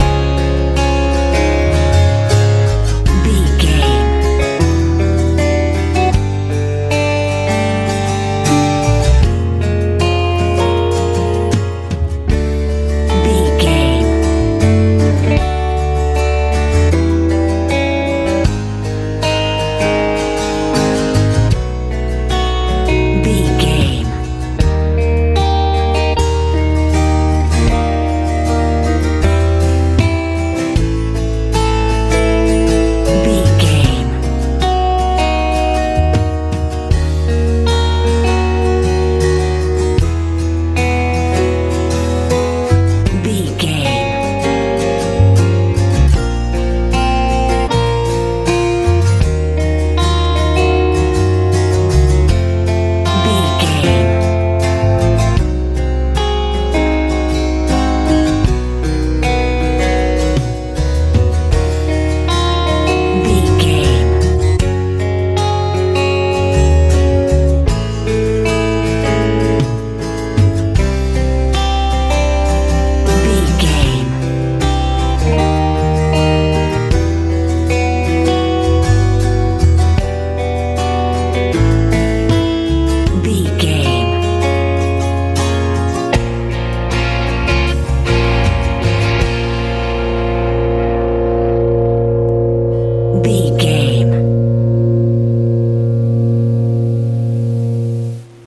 lite pop feel
Ionian/Major
C♯
light
mellow
piano
electric guitar
acoustic guitar
bass guitar
drums